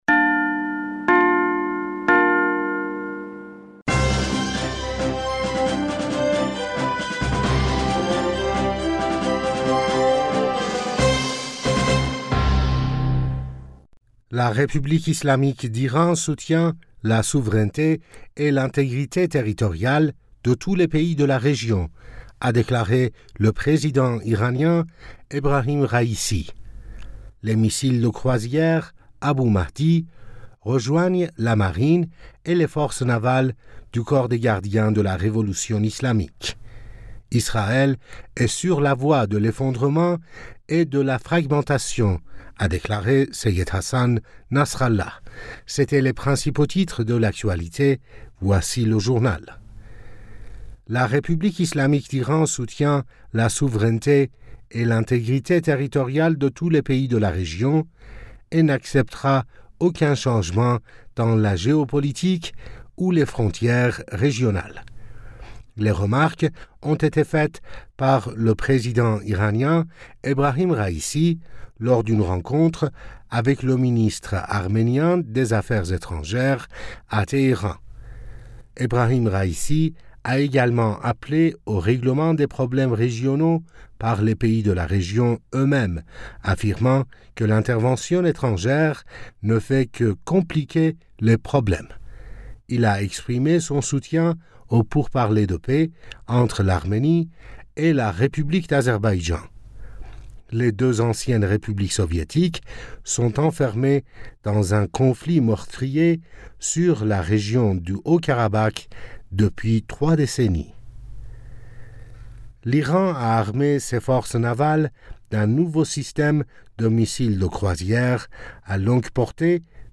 Bulletin d'information du 25 Juillet 2023